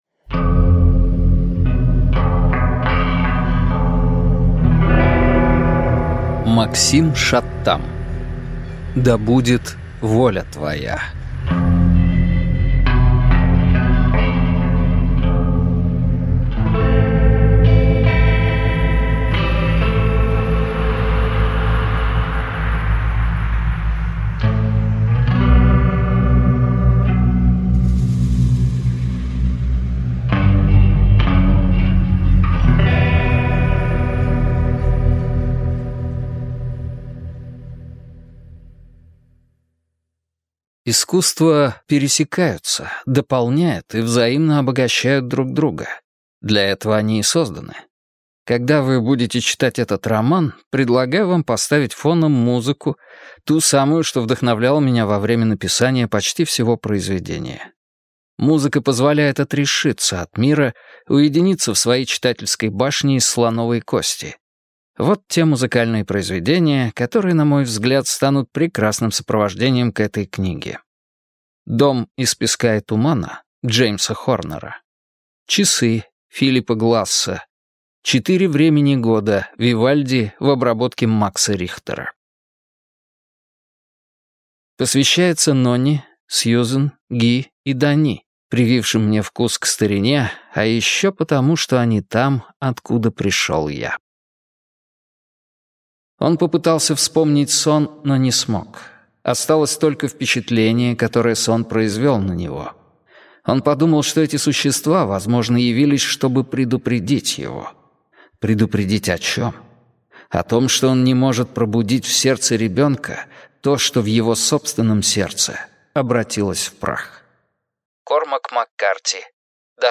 Аудиокнига Да будет воля Твоя | Библиотека аудиокниг